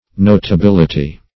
Notability \Not`a*bil"i*ty\, n.; pl. Notabilities. [Cf. F.